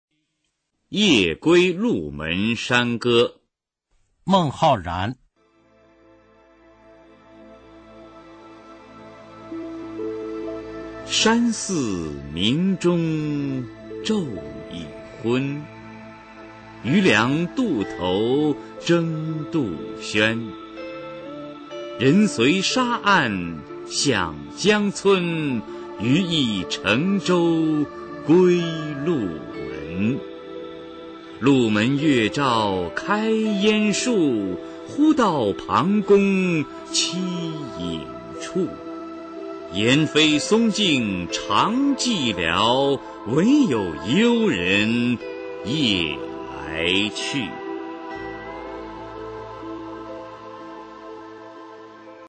[隋唐诗词诵读]孟浩然-夜归鹿门山歌 配乐诗朗诵